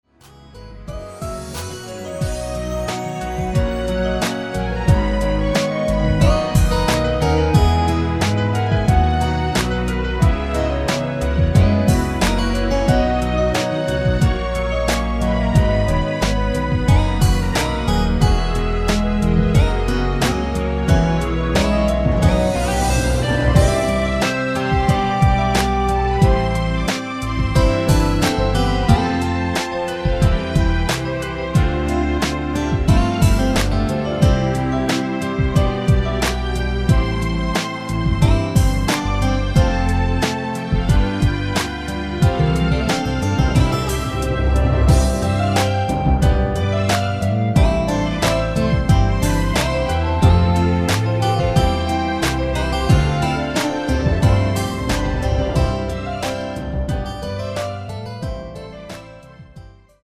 -1)멜로디 MR 멜로디 포함된 MR 입니다.
◈ 곡명 옆 (-1)은 반음 내림, (+1)은 반음 올림 입니다.
멜로디 MR이라고 합니다.
앞부분30초, 뒷부분30초씩 편집해서 올려 드리고 있습니다.